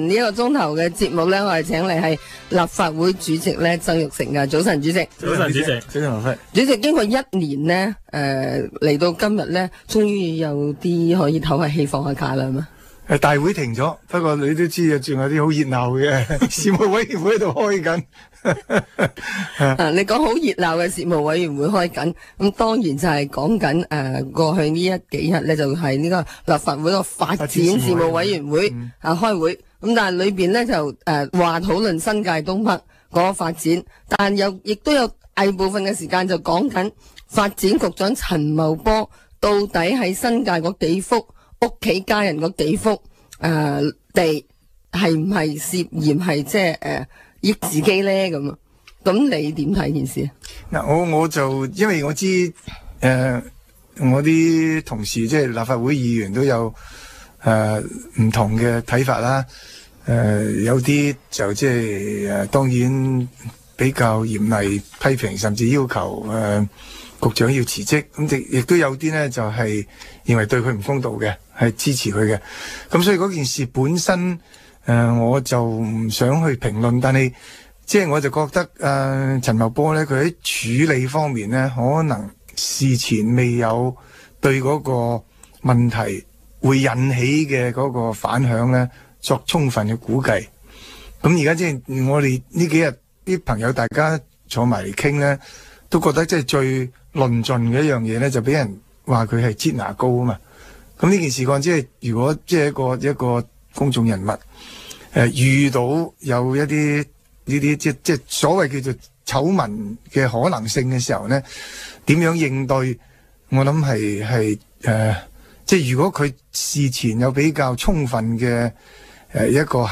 商業電台《政經星期六》訪問